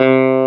CLAV2SFTC3.wav